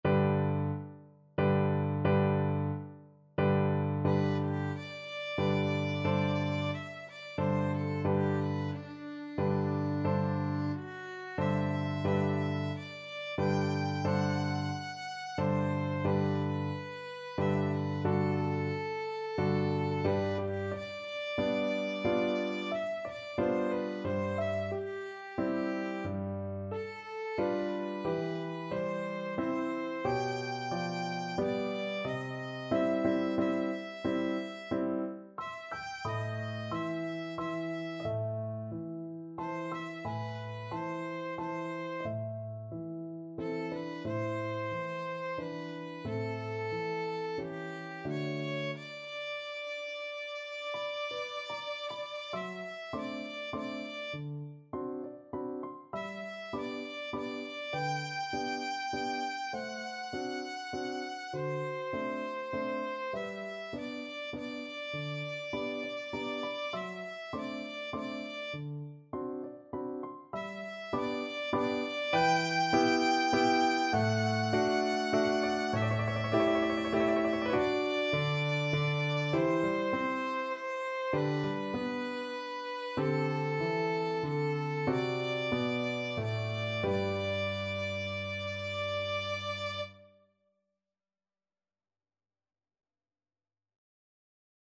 Violin
G major (Sounding Pitch) (View more G major Music for Violin )
3/4 (View more 3/4 Music)
~ = 90 Allegretto moderato
Classical (View more Classical Violin Music)